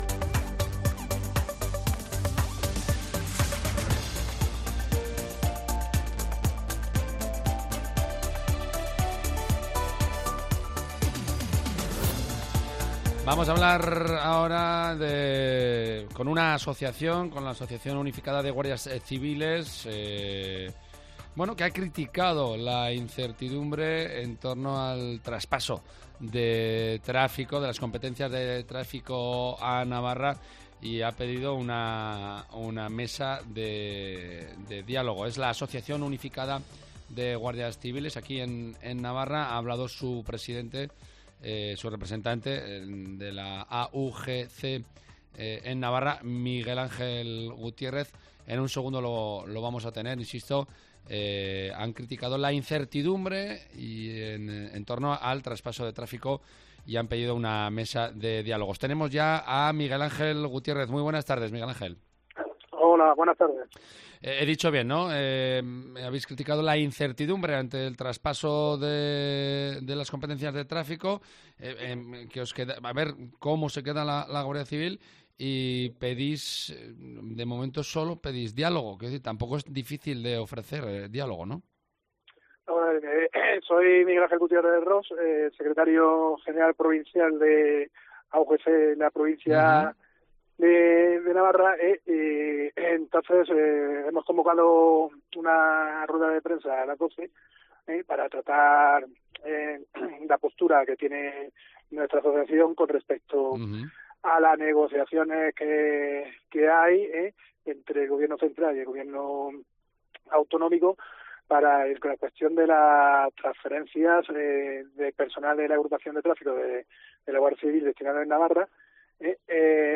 Representantes de AUGC